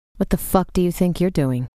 The interesting tidbit is that a decoy song also got leaked. It’s a mostly empty track containing the following prose by the artist herself: